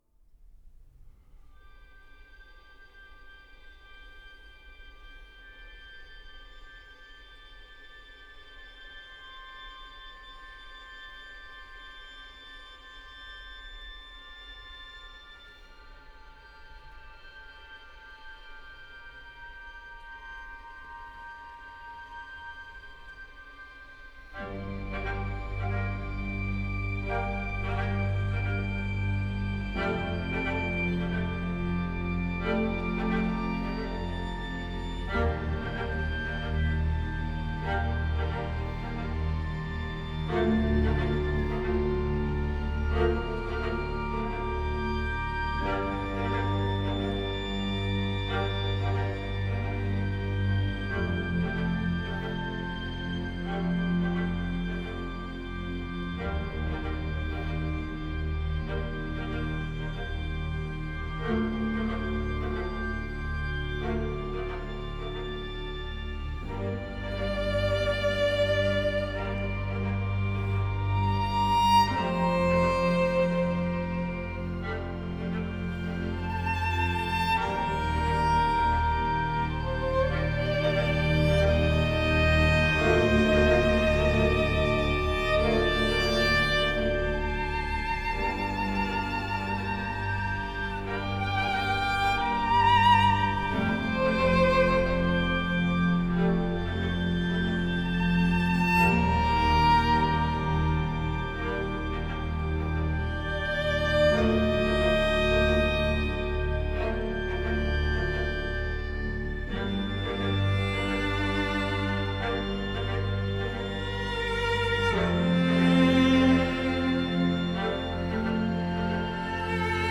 موسیقی بیکلام موسیقی فاخر